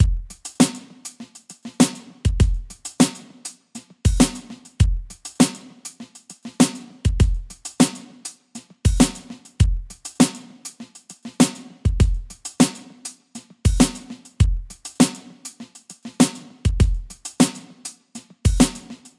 SOUL FUNK Groove Steve Gadd Style 100 BPM
描述：在小号和大号之间用鬼魂音符和paradiddles进行沟槽。没有镲片。这是我的电子鼓套装的真实演奏和录音。
Tag: 100 bpm Soul Loops Drum Loops 3.23 MB wav Key : Unknown